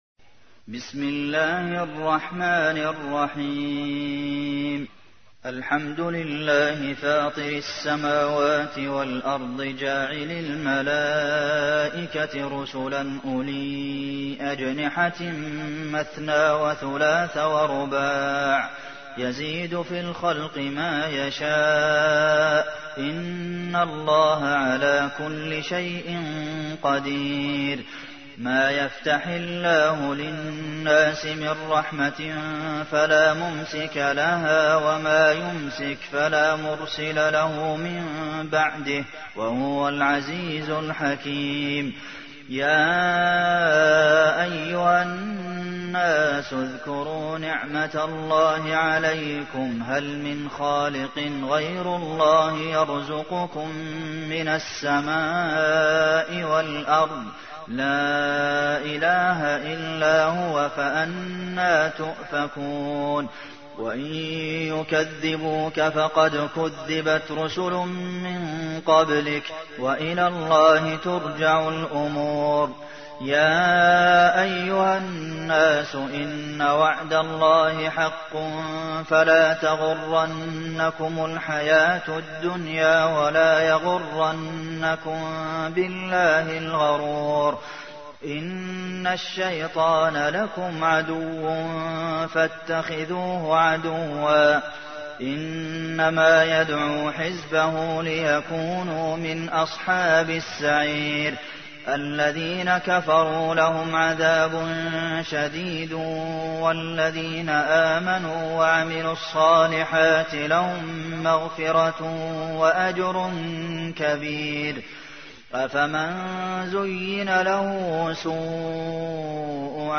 تحميل : 35. سورة فاطر / القارئ عبد المحسن قاسم / القرآن الكريم / موقع يا حسين